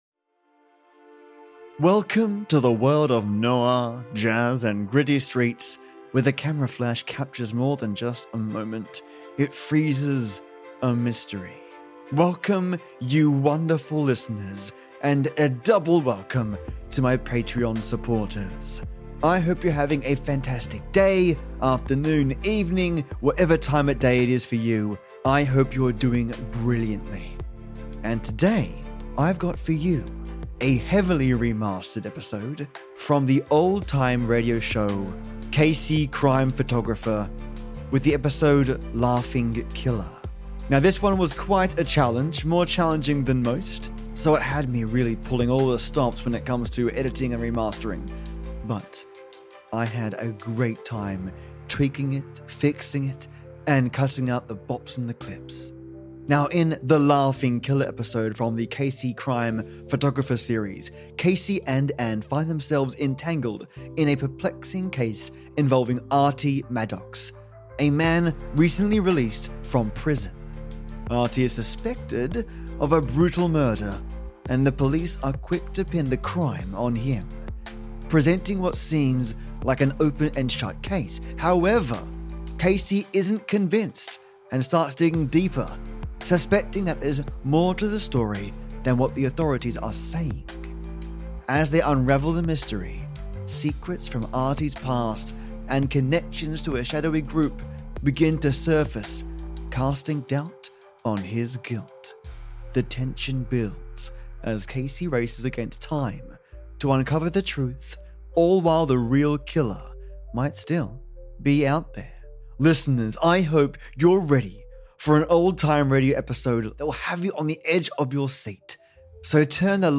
The Laughing Man - OLD TIME RADIO!